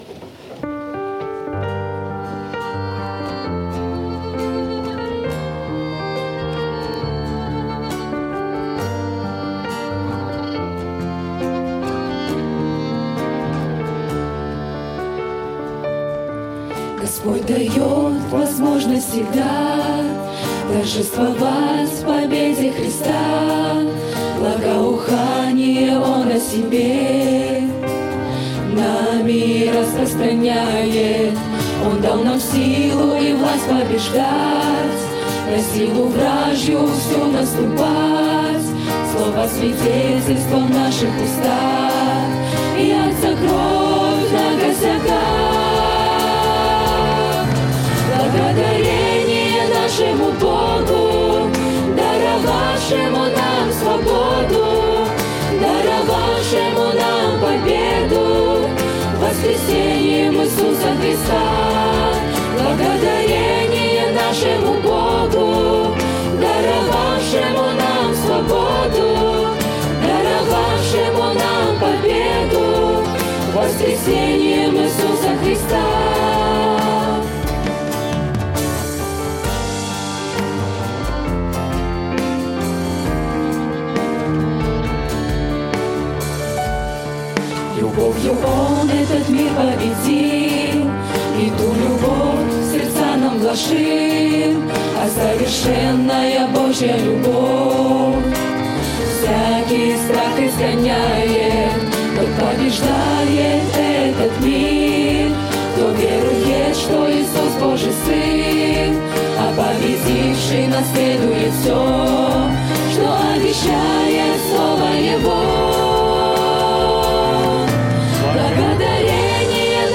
Псалмы группы